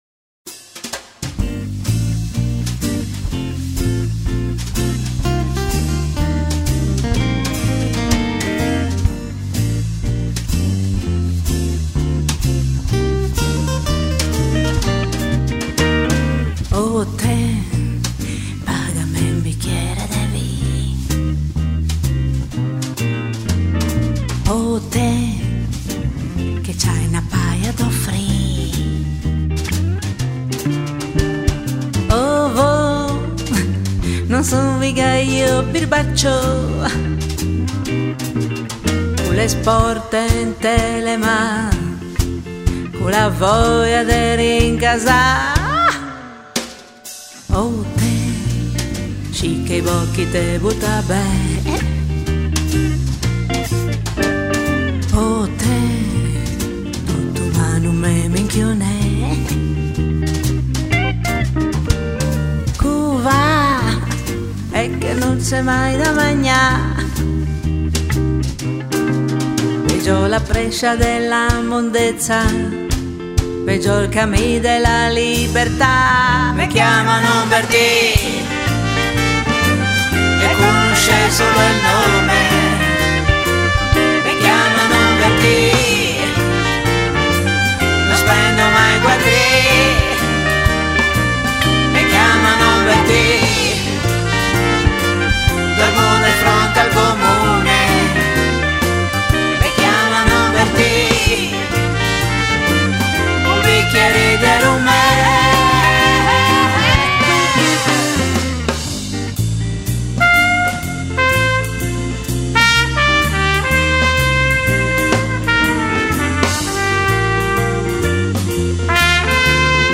un mix di Pop e Afrobeat